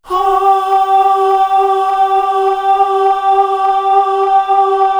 Index of /90_sSampleCDs/Best Service ProSamples vol.55 - Retro Sampler [AKAI] 1CD/Partition C/CHOIR AHH